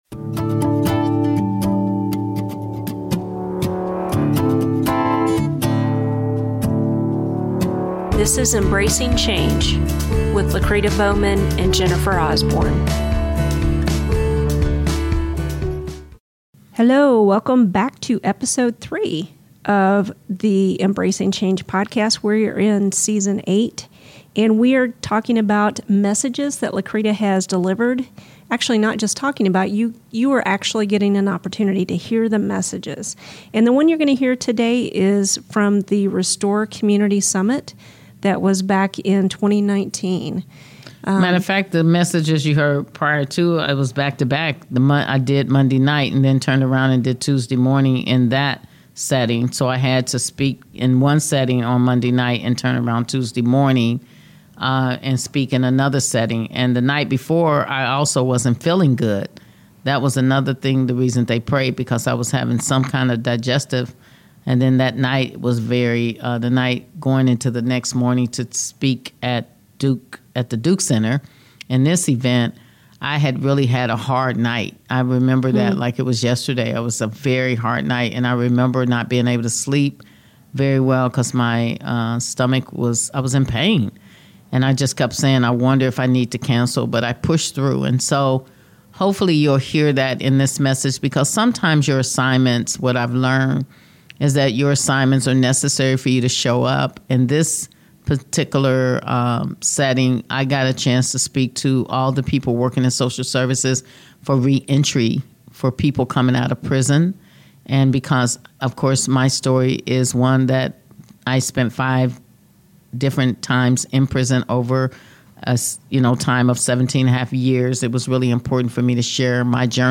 Restore Community Summit: Hamilton County Office of Reentry